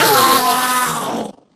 Sound / Minecraft / mob / endermen / death.ogg
death.ogg